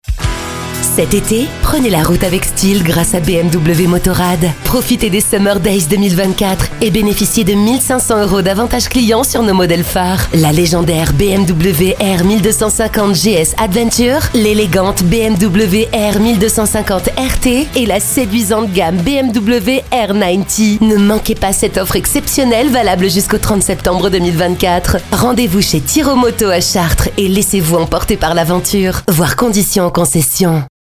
Voix off
Ma voix est medium/grave. A la fois ronde et gourmande. Un petit grain suave lui confère un côté sexy.
éraillée
voix-grave